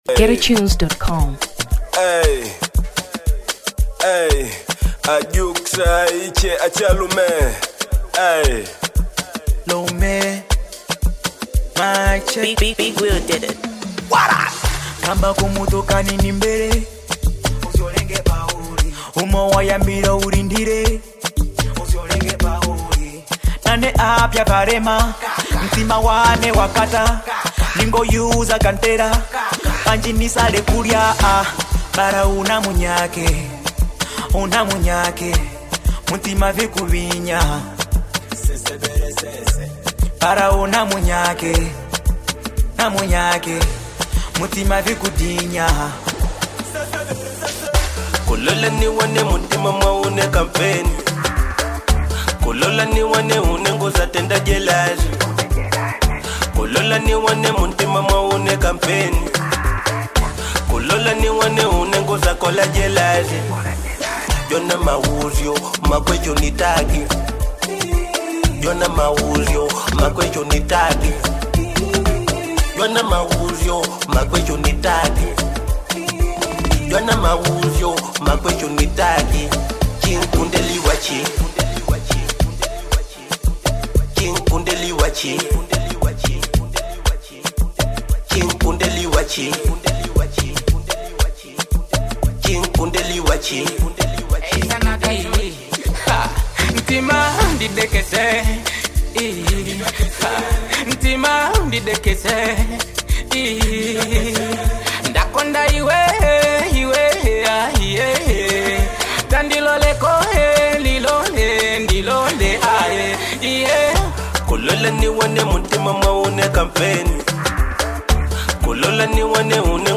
Afro Fusion 2023 Malawi